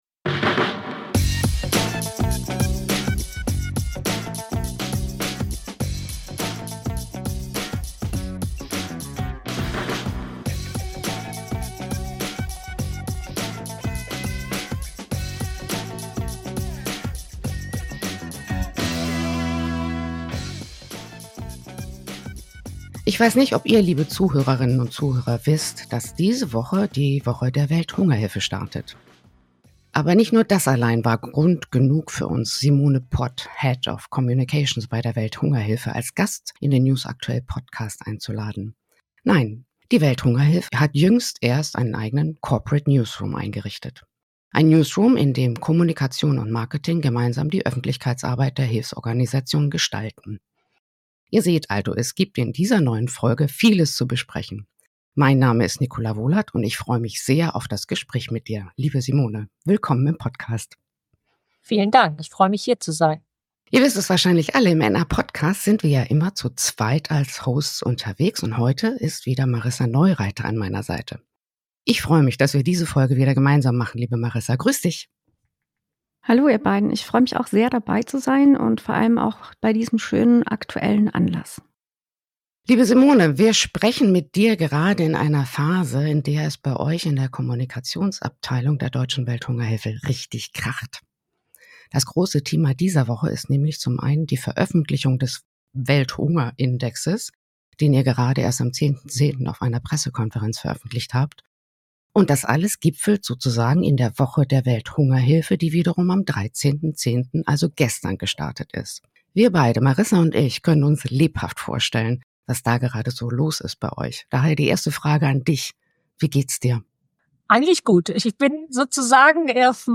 Außerdem werfen wir einen Blick auf die aktuelle Woche der Welthungerhilfe, den Welthunger-Index und die Rolle von Frauen im Kampf gegen Hunger und Krisen. Ein Gespräch über Wandel, Verantwortung und die Zukunft der humanitären Kommunikation.